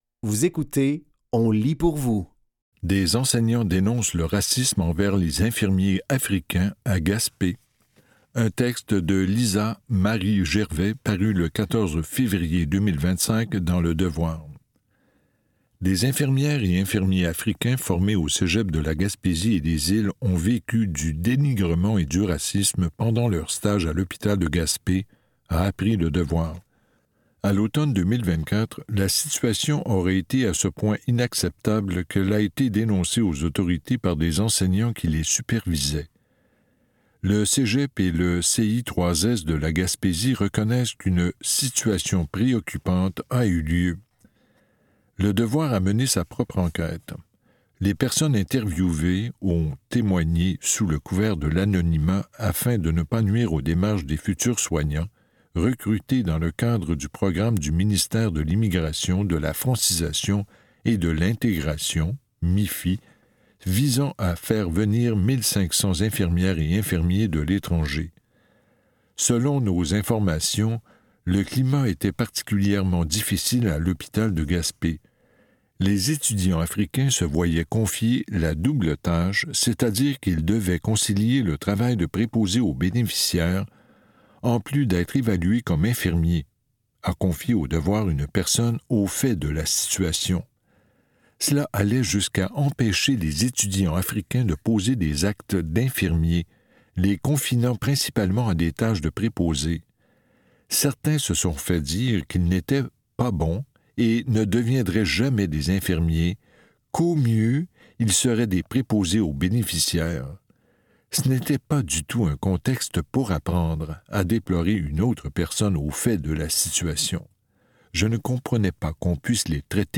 Dans cet épisode de On lit pour vous, nous vous offrons une sélection de textes tirés des médias suivants : Le Devoir, Le Nunavoix, et Hebdo Rive-Nord.